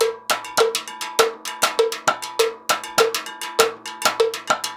Index of /90_sSampleCDs/USB Soundscan vol.36 - Percussion Loops [AKAI] 1CD/Partition B/03-100STEELW